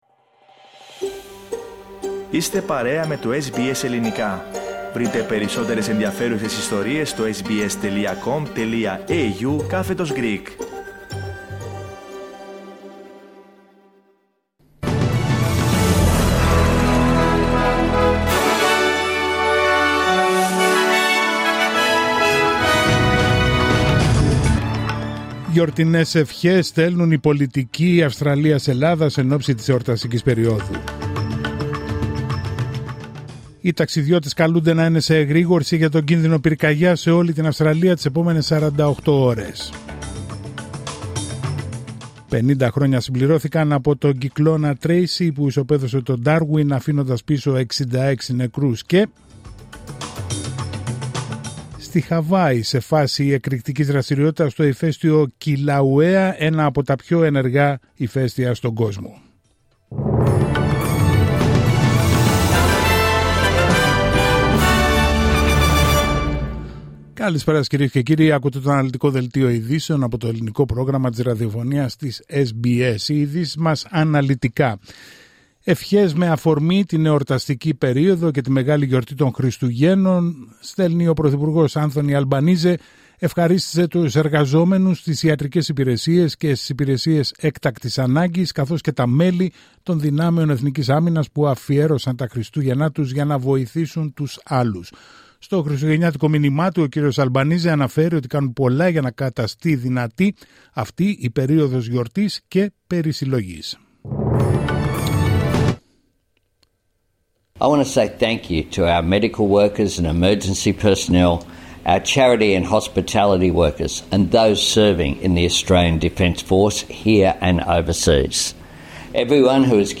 Δελτίο ειδήσεων Τρίτη 24 Δεκεμβρίου 2024